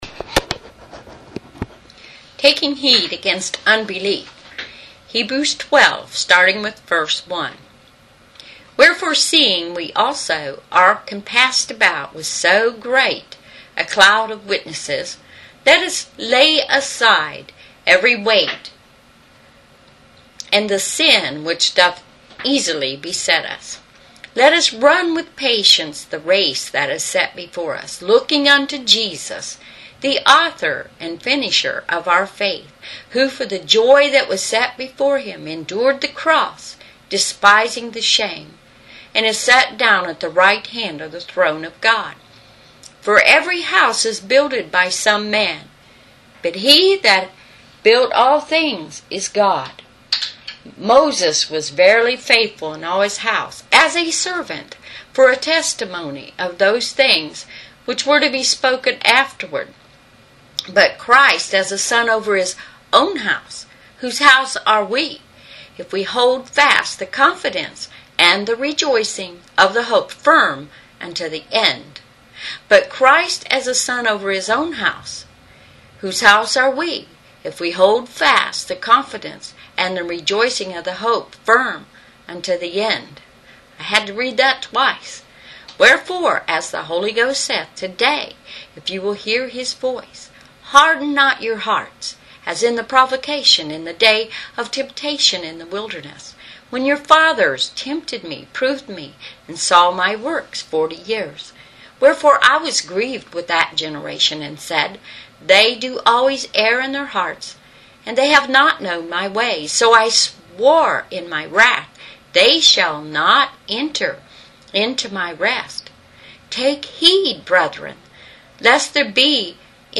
Various Sermons and topics, audio messages